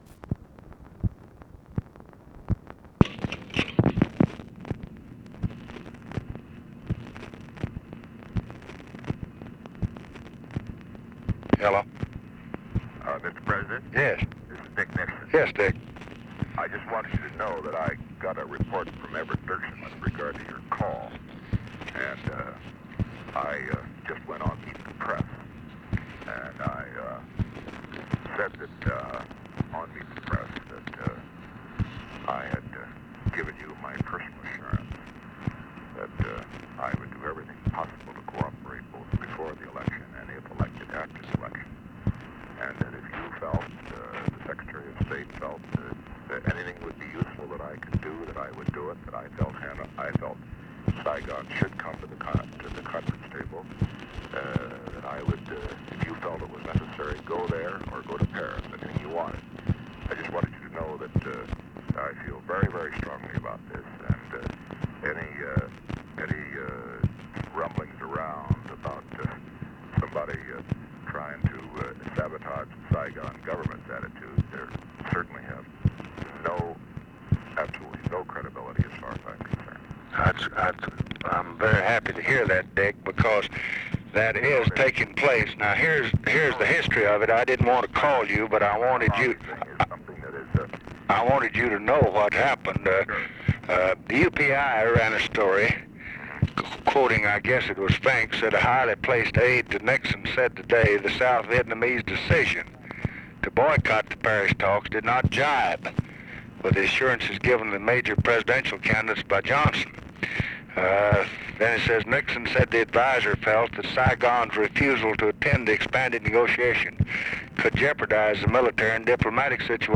Conversation with RICHARD NIXON, November 3, 1968
Secret White House Tapes